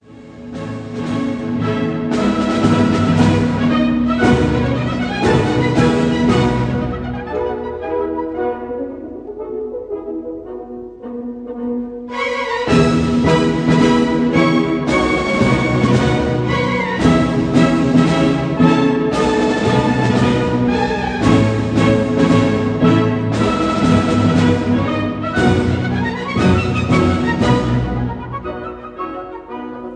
Recorded in 1952